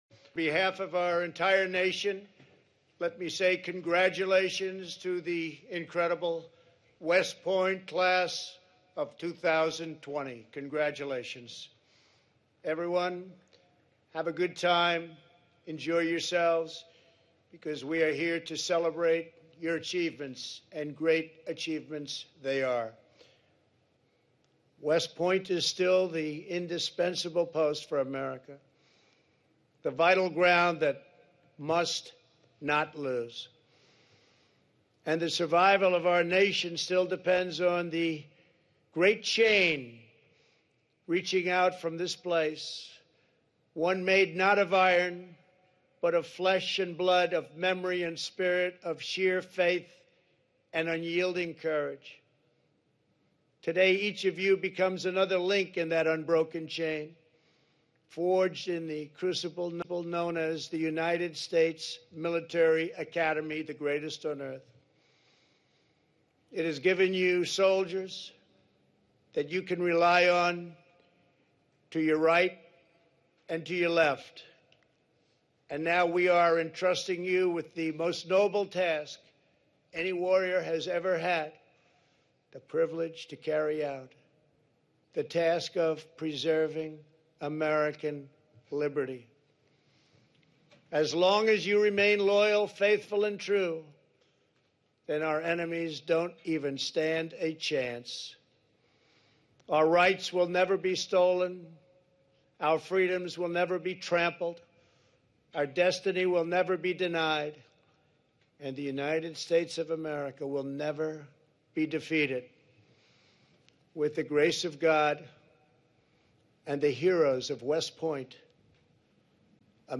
No doubt they are reading their notes vs. speaking spontaneously speaking.  Take a listen to them give a 2020 commencement speech.
Loudness/Projection Low, soft Stays the same throughout
Pitch Lower pitch than Trump Higher pitch than Biden
Tone Empathetic, gentle and passionate, reassuring Serious, direct
Expressive Intonation More conversational (varied pitch) Monotone
Clearer than Biden
Short sentences. Pauses are present.  Choppier sound with bytes of information.
Faster than Joe Biden but not too fast.
DonaldTrump2020CommencementSpeech.m4a